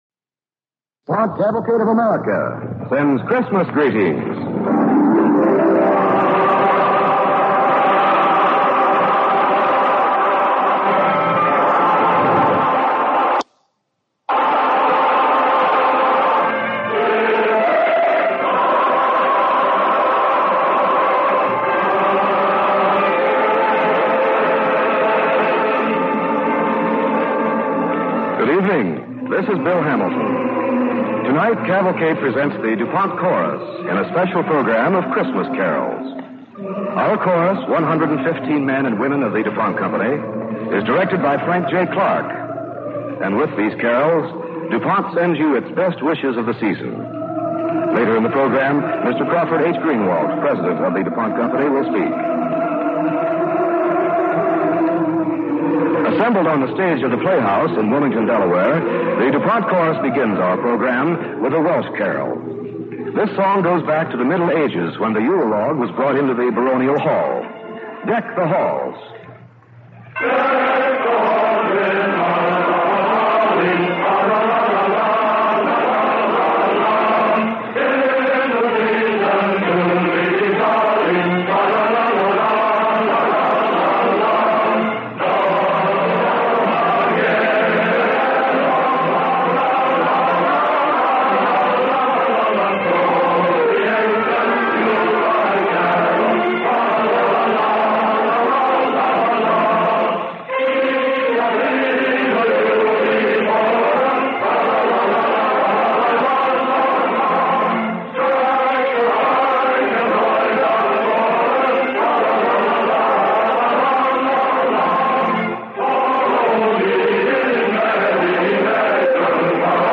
The DuPont Chorus Sings Christmas Carols